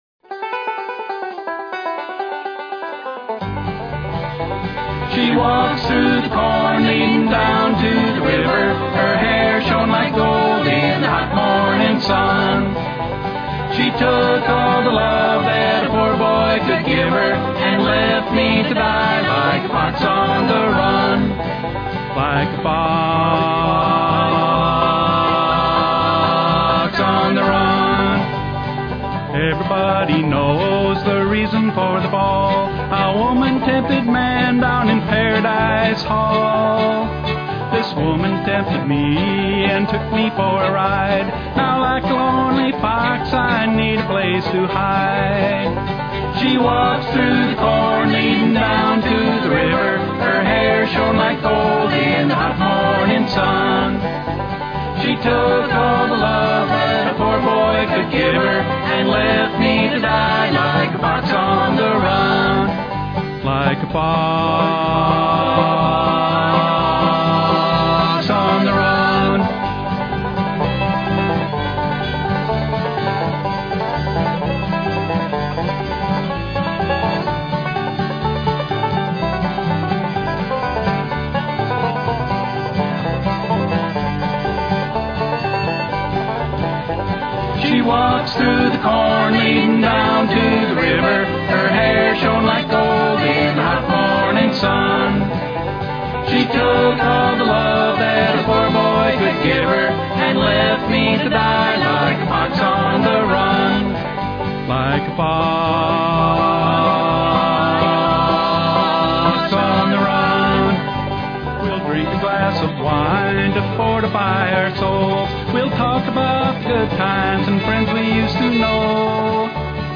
Traditional Folk Music in